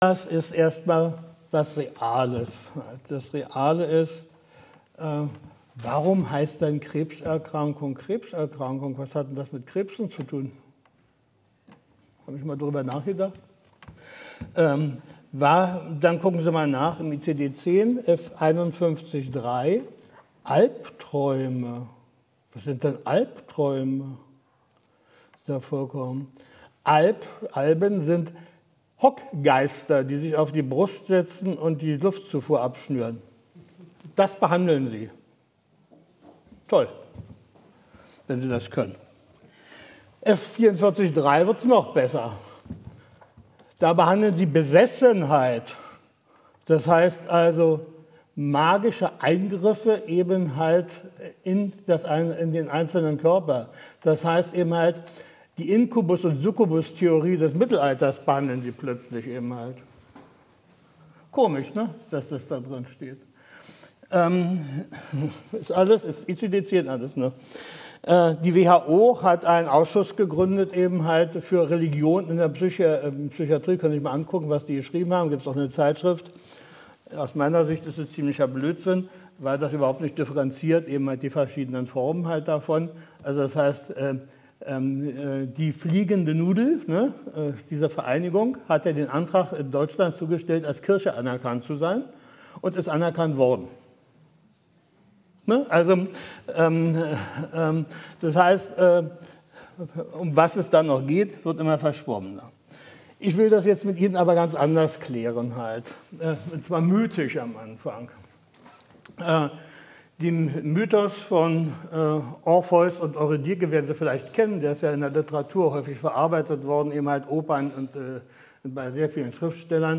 Vortrag 6.5.2023, Siegburg: 28. Rheinische Allgemeine PSYCHOtherapietagung - Psychotherapie nach der Zeitwende